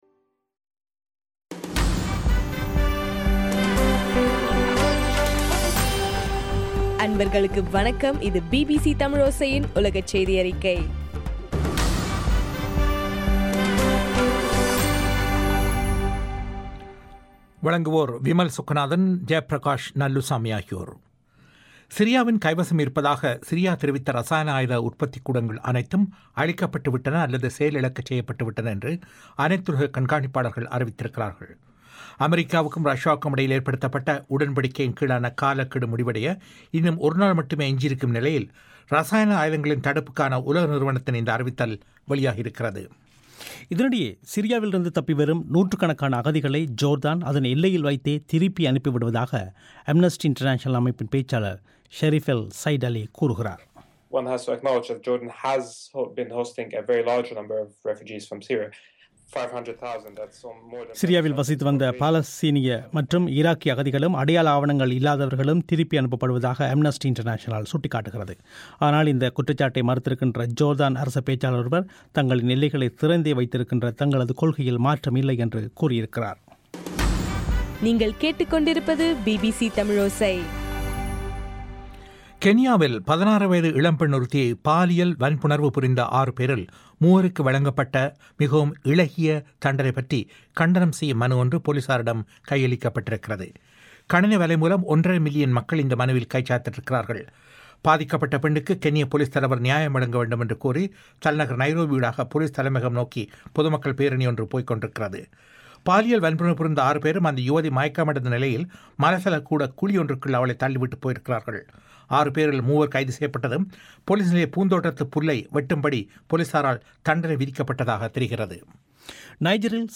அக்டோபர் 31 தமிழோசையின் உலகச் செய்திகள்